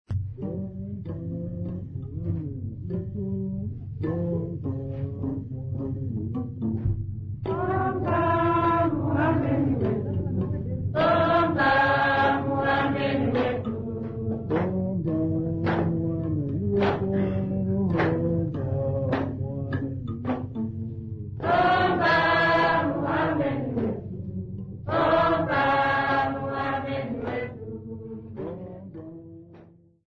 Sambiu church music workshop participants
Sacred music Namibia
Choral music Namibia
Mbira music Namibia
Africa Namibia Sambiu mission, Okavango sx
field recordings
Church song with mbira and clapping accompaniment.